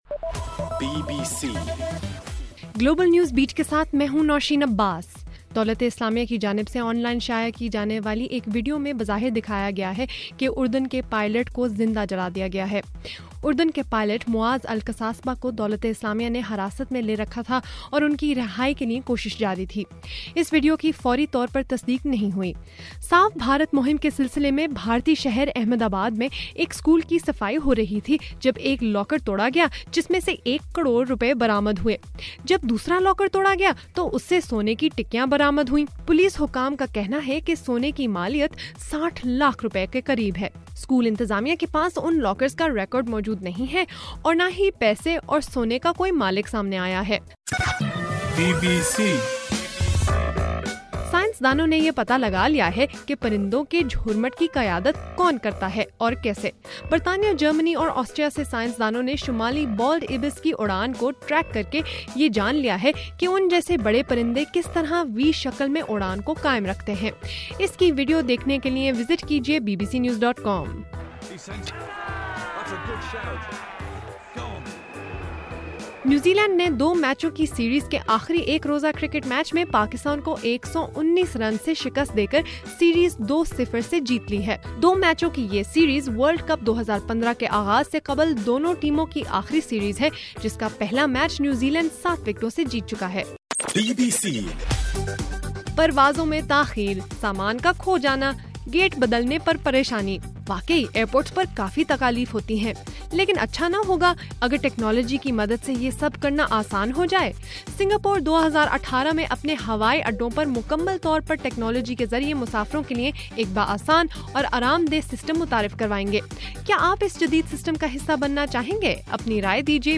فروری 3 : رات 12 بجے کا گلوبل نیوز بیٹ بُلیٹن